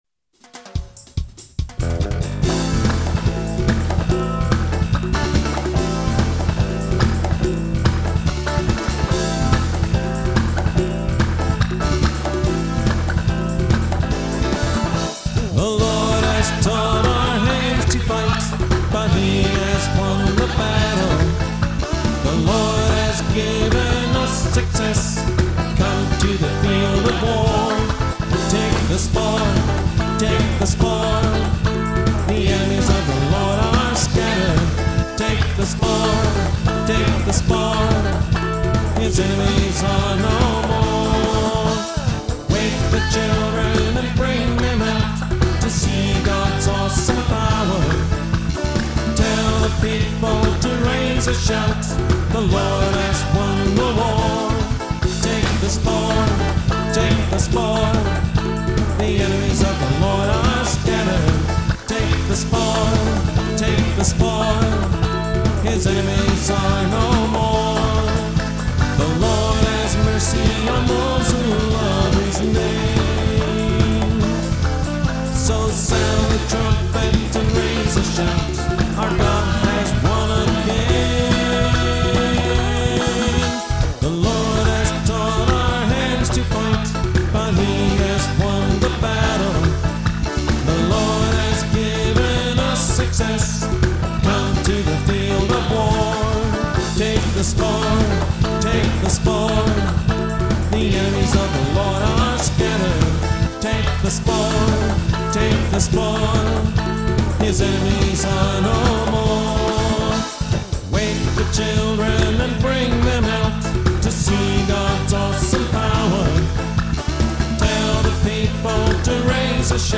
recorded live at Spirit Wind Church in Kokomo, Indiana